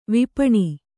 ♪ vipaṇi